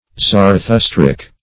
Search Result for " zarathustric" : The Collaborative International Dictionary of English v.0.48: Zarathustrian \Zar`a*thus"tri*an\, Zarathustric \Zar`a*thus"tric\, a. Of or pertaining to Zarathustra, or Zoroaster; Zoroastrian.